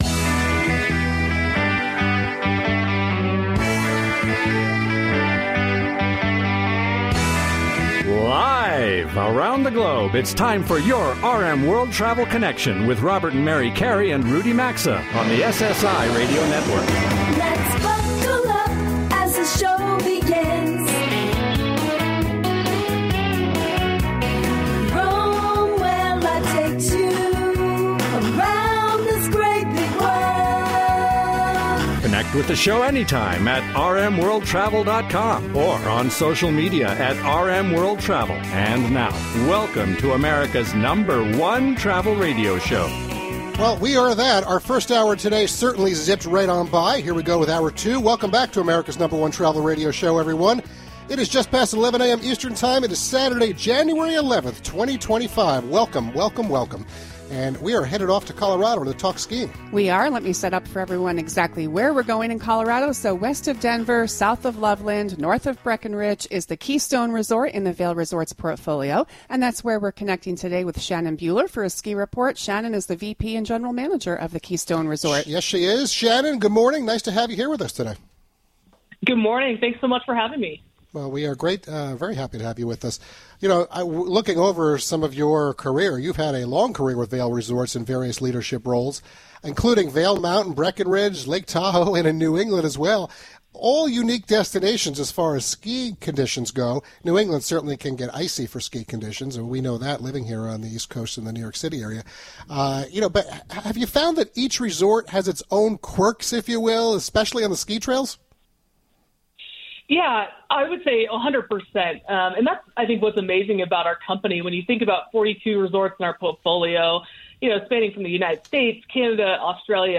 They provide straight-forward advice and commentary, inside scoop, tips/trends and more, as they connect with the audience and skillfully cover the world of travel, culture, and its allure through modern segments, on-location remote broadcasts, on-air showcasing and lively banter.